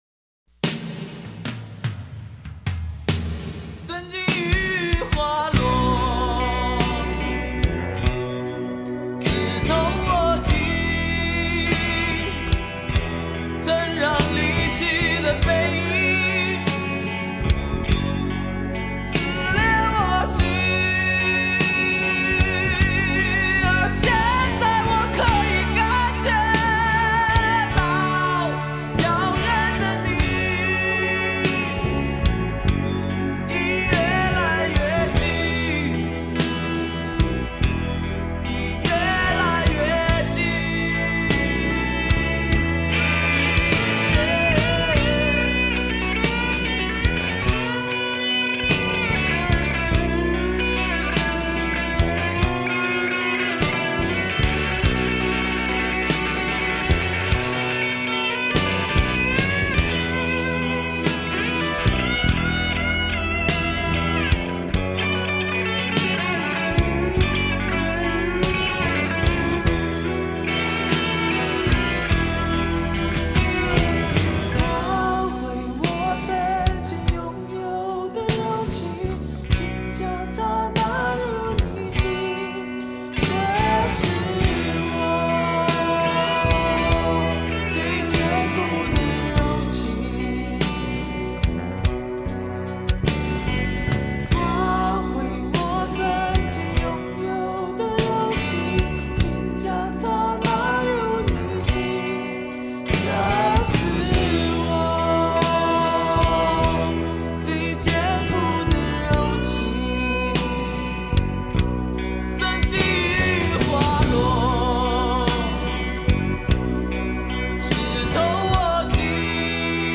显现出成熟的蓝调曲风
吉它演奏在结尾部分转为主角，时而留白，更表现出一种悲伤之后的空虚感。